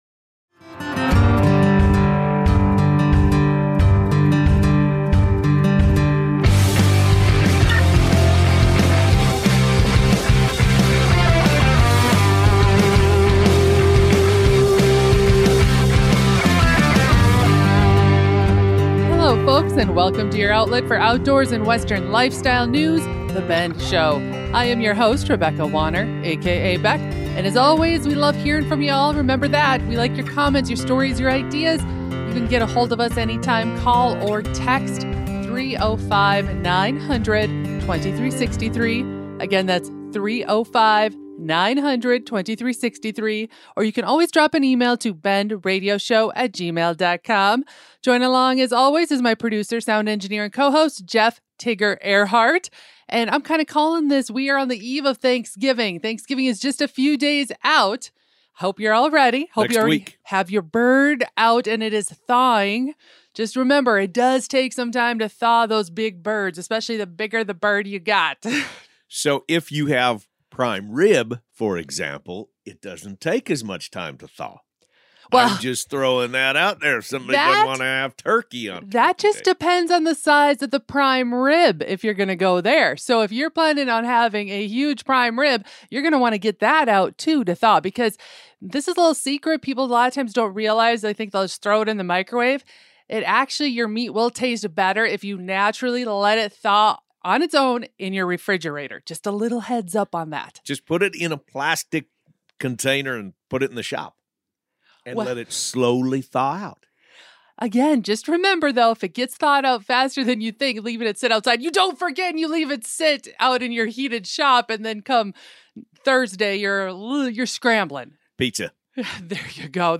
The Bend Radio Show and Podcast Episode 264